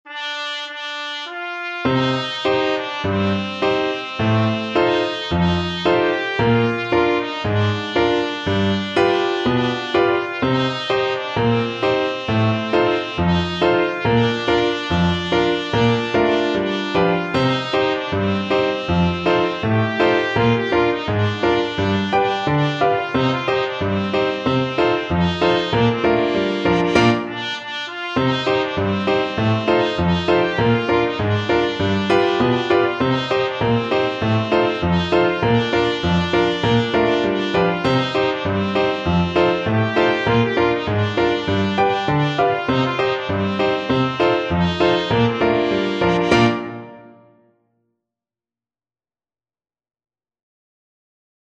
Trumpet
Traditional Music of unknown author.
Bb major (Sounding Pitch) C major (Trumpet in Bb) (View more Bb major Music for Trumpet )
First time accel. throughout
2/2 (View more 2/2 Music)
Bb4-G5
Gypsy music for trumpet